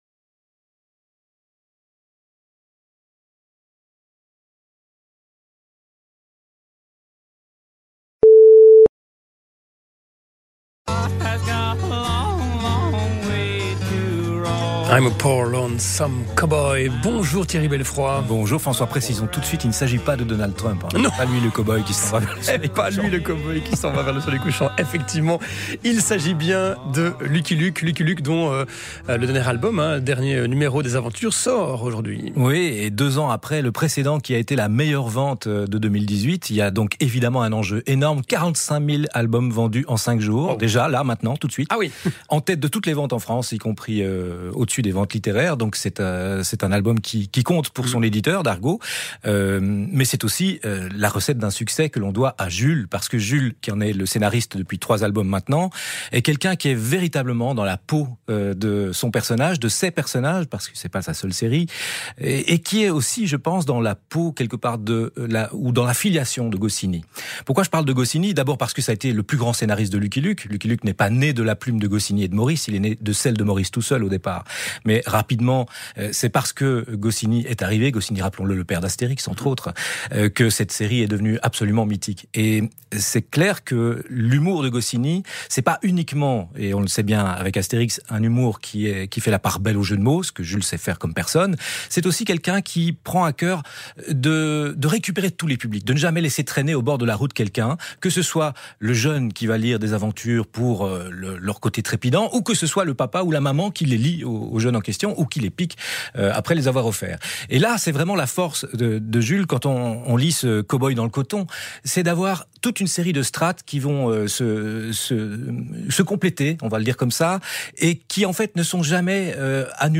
Vous allez écouter deux fois un extrait de l’émission de radio La Première de la RTBF.